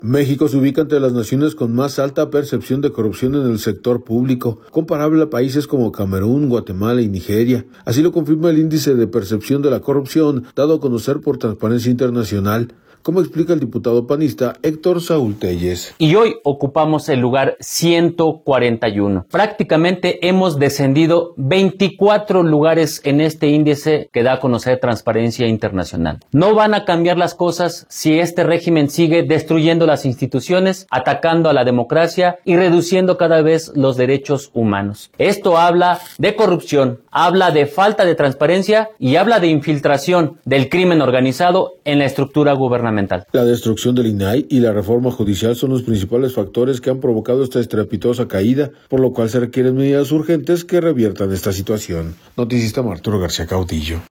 México se ubica entre las naciones con más alta percepción de corrupción en el sector público, comparable a países como Camerún, Guatemala y Nigeria, así lo confirma el Índice de Percepción de la Corrupción, dado a conocer por Transparencia Internacional, como explica el diputado panista Héctor Saúl Téllez.